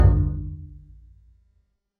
Taiko Drum Hit
A massive taiko drum hit with deep resonance, skin vibration, and room decay
taiko-drum-hit.mp3